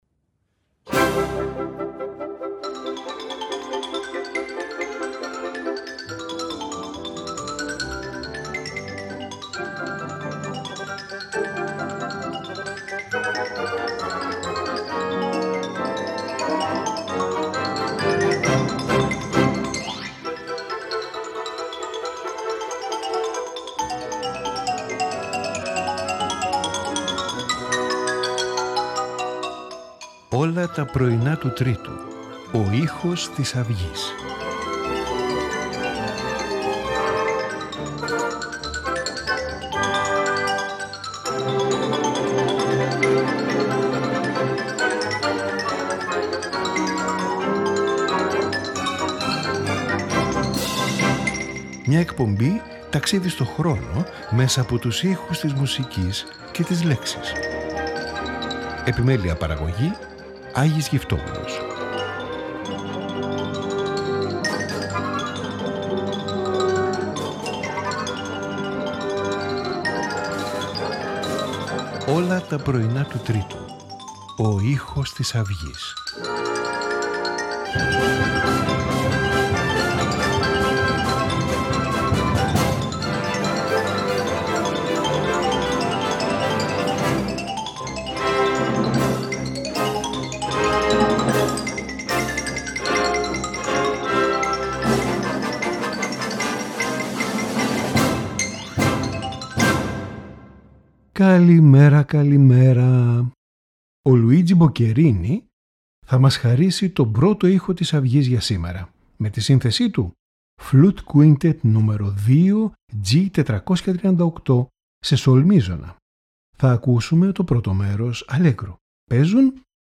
Flute Quintet No.2 in G major
Piano Trio No.2 in G major
Violin Concerto in E minor
Piano Concerto No.1 in F-sharp minor